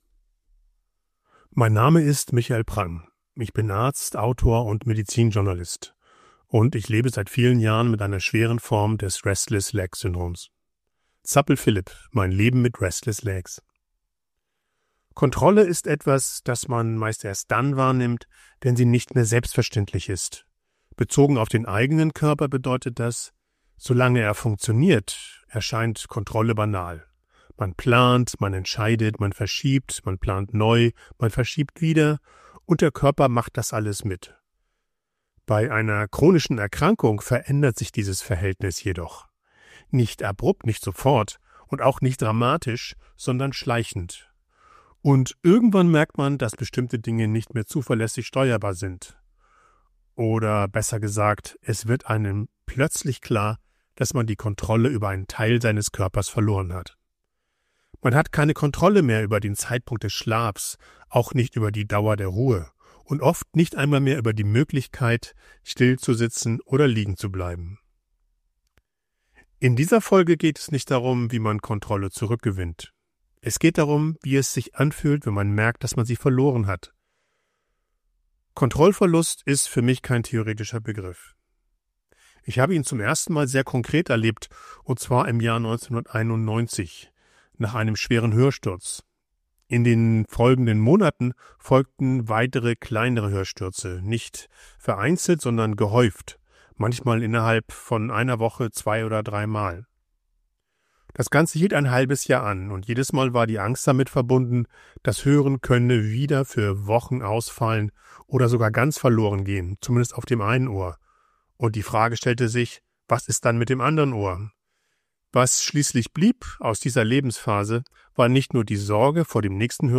Ich spreche als Arzt und Betroffener über Alltag, Belastung und